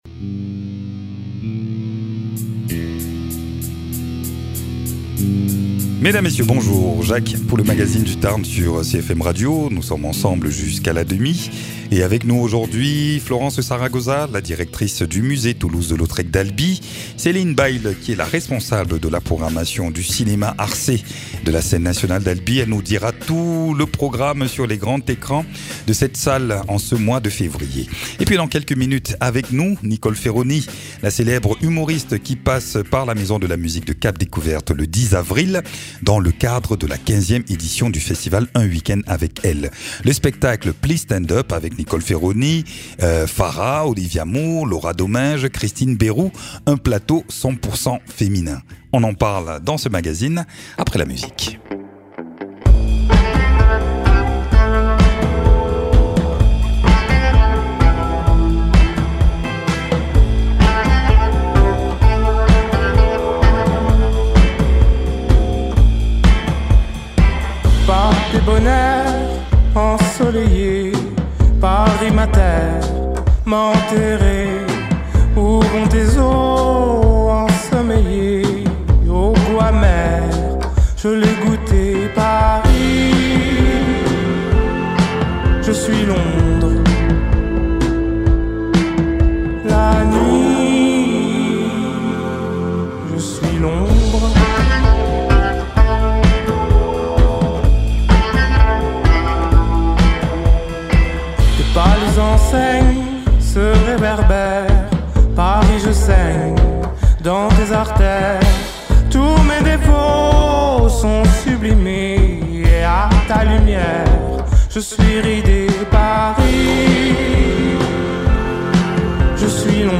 Invité(s) : Nicole Ferroni, humoriste